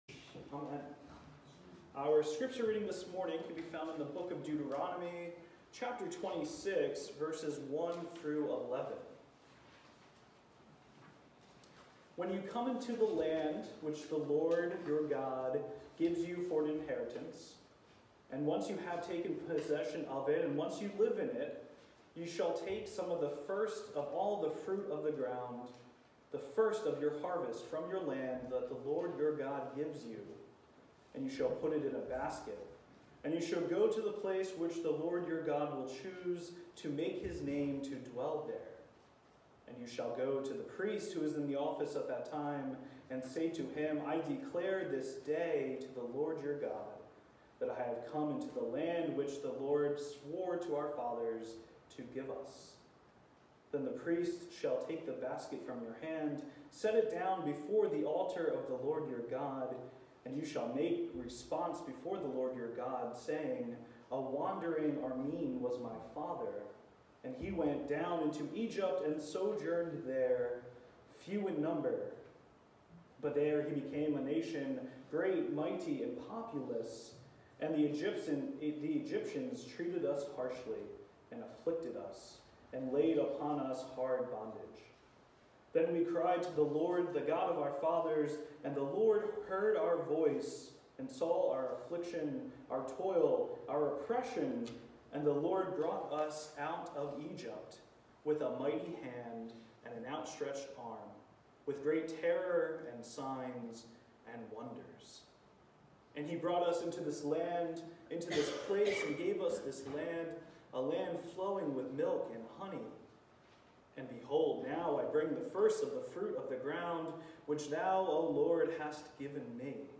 Broadway-UMC-Sermon-3_10_19.mp3